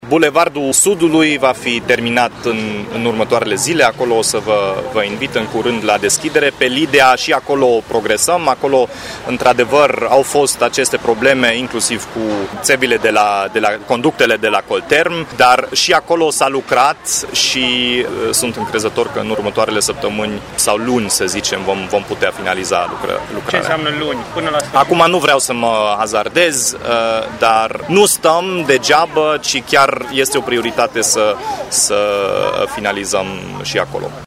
Edilul evită să înainteze un termen până la care proiectul va fi terminat, dând asigurări, în schimb, că bulevardul Sudului va fi gata în câteva zile: